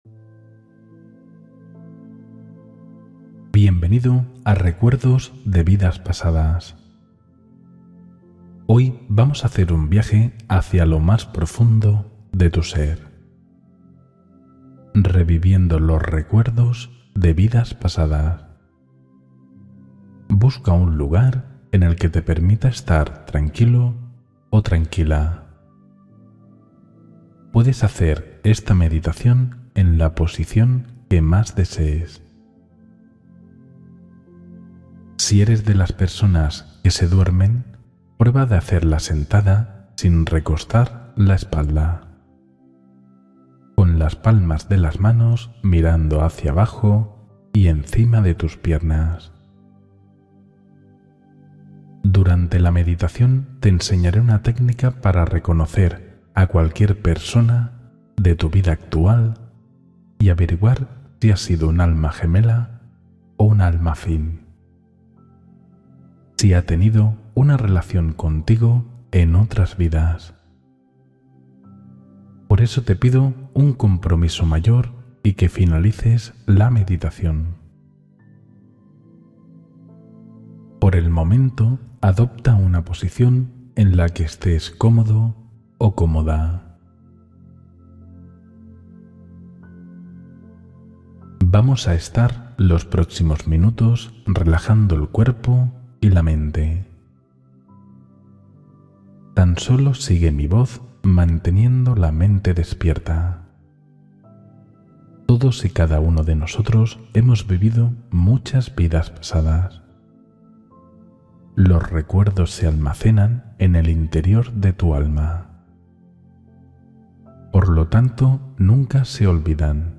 Exploración Simbólica del Pasado Interior: Meditación de Imágenes y Memoria